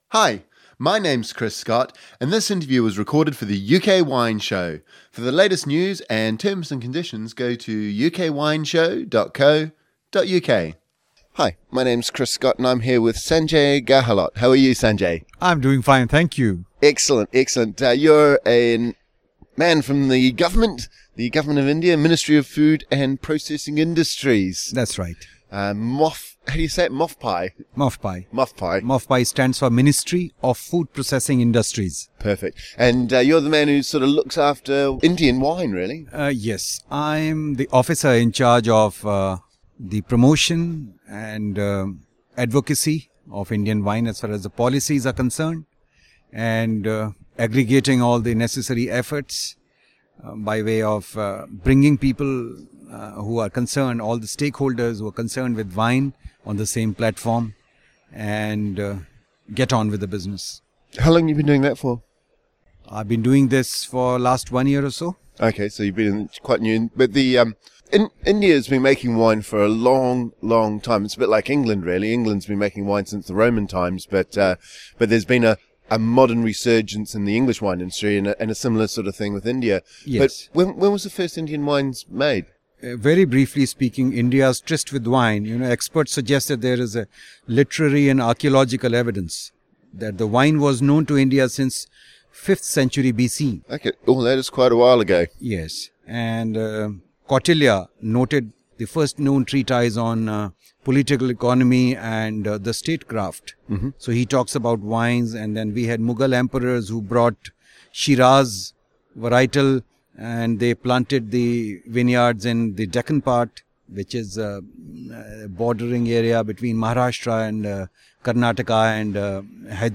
» Listen to the full UK Wine Show